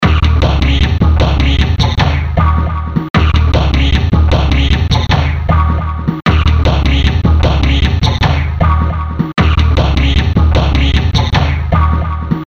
Dark Funk.wav